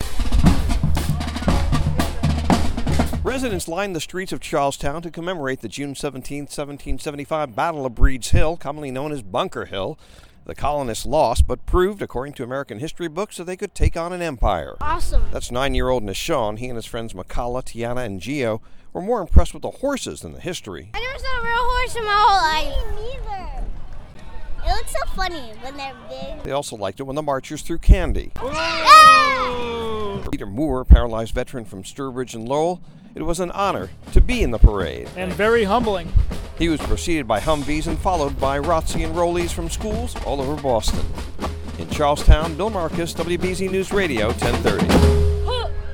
(CHARLESTOWN) JUN 10 – THE NEIGHBORHOOD OF CHARLESTOWN HELD A PARADE TODAY JUST LIKE THEY HAVE ANNUALLY SINCE 1786 TO COMMEMORATE THE BATTLE OF BUNKER HILL.